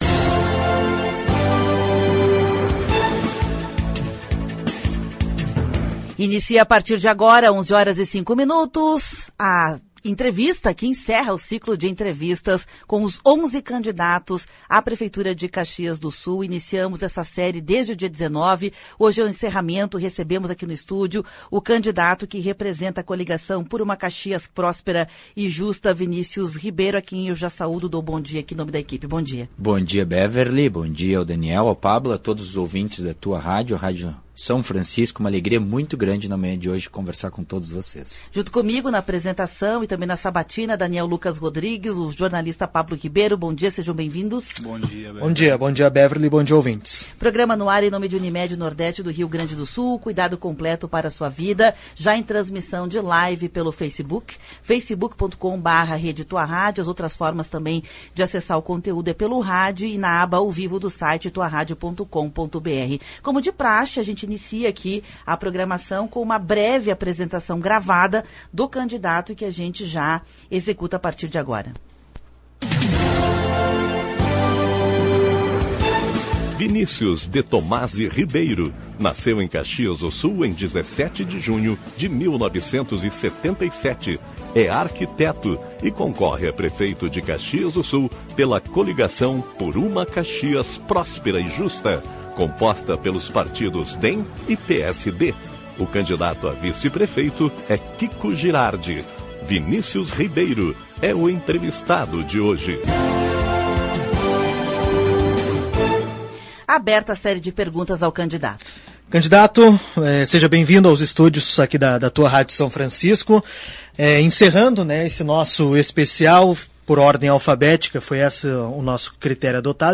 A Tua Rádio São Francisco encerra a série de entrevistas com os candidatos a prefeito de Caxias do Sul. De segunda a sexta, das 11h às 12h, os candidatos foram entrevistados por jornalistas da Central de Conteúdos.
Confira a entrevista completa no áudio acima.